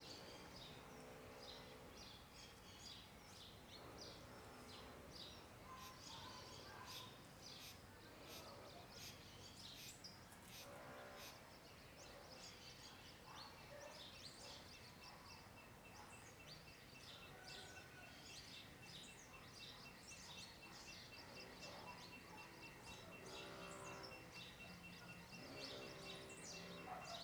Trovoes na serra vozes criancas Começando chuva , Galo , Grilo , Pássaros , Serra , Trovões , Vozerio criancas São Domingos, Goiás Stereo